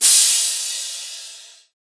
Crashes & Cymbals
MZ Crash [Southside].wav